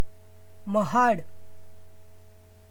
pronunciation [məɦaːɖ]) is a city in Raigad district (formerly Kulaba district) situated in the North Konkan region of Maharashtra state, India.